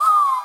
Swervo Whistle FX.wav